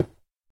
Звуки ломания и установки блоков
stone3.mp3